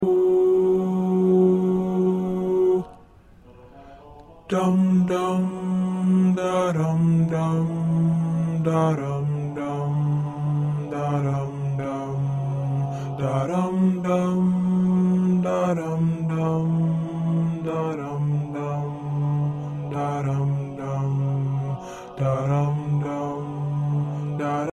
Practice tracks are based on the sheet music.